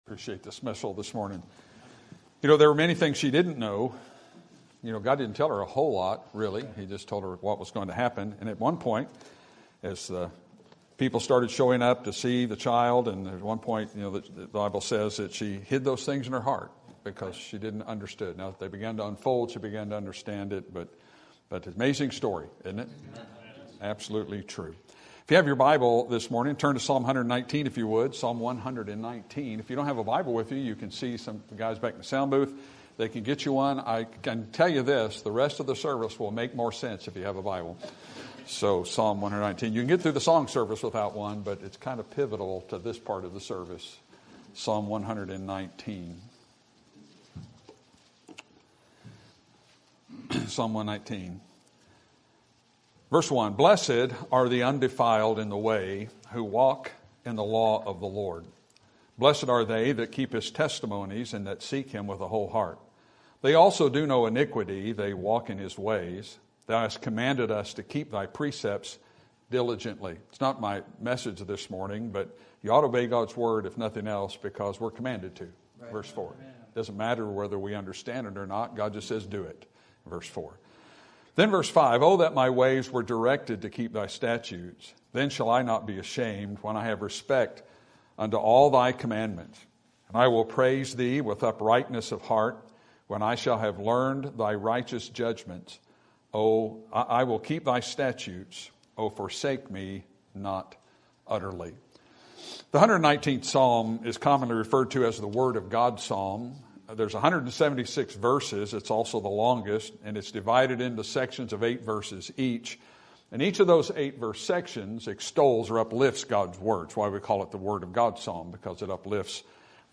Sermon Topic: General Sermon Type: Service Sermon Audio: Sermon download: Download (25.61 MB) Sermon Tags: Psalm Word Guilt Ashamed